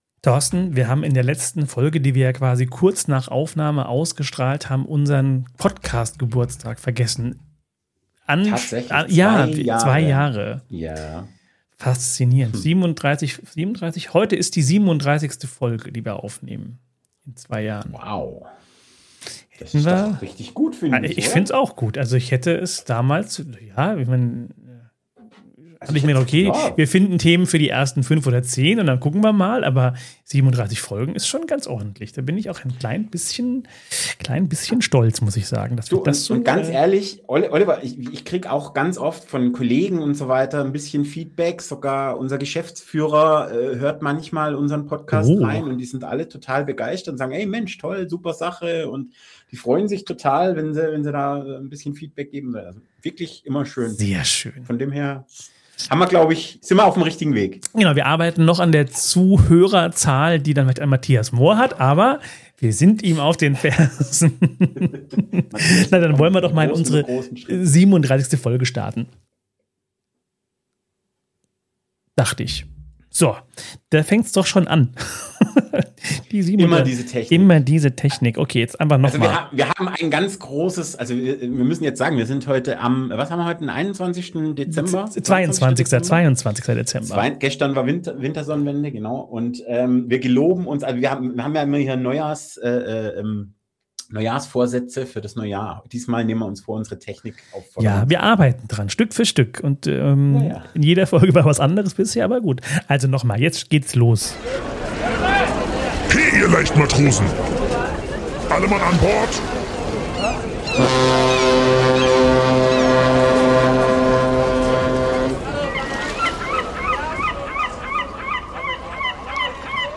lockerer Weihnachtstalk über Flussschiffe, Adventsreise, und unsere Pläne fürs nächste Jahr